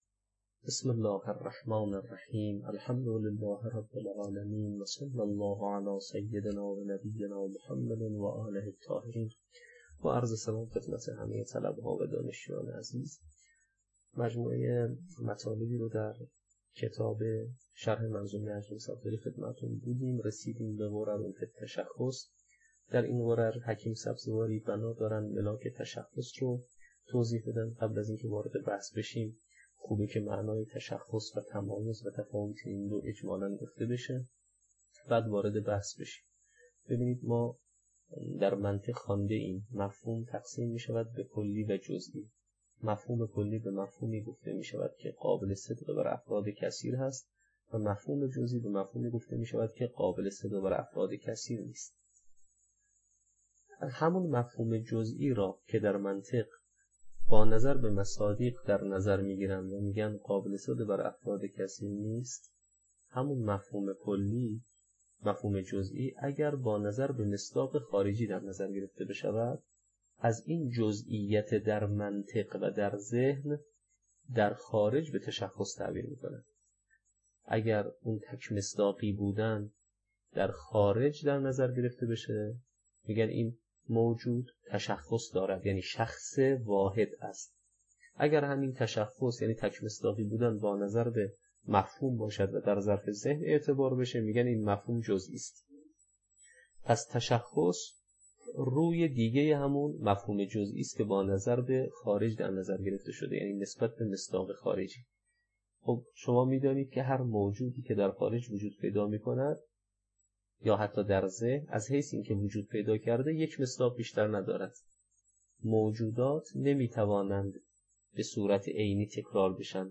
تدریس کتاب شرح منظومه